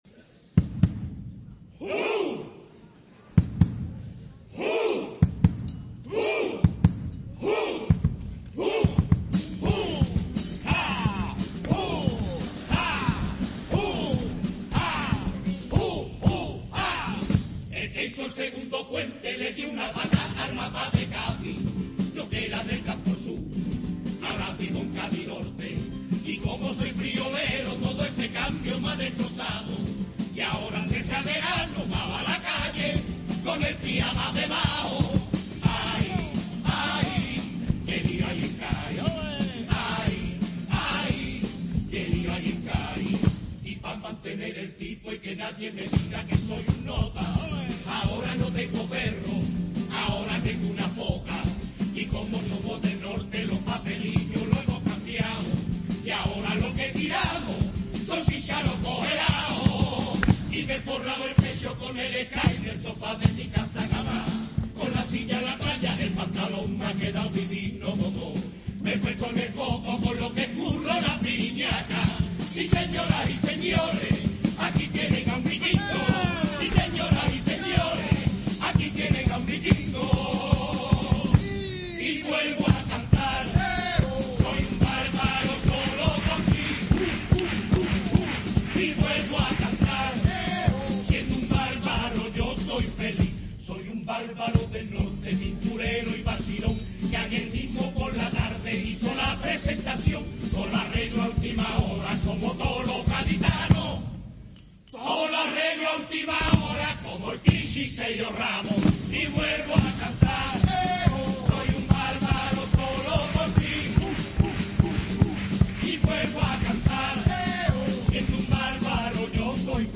Chirigota Los de Cádiz Norte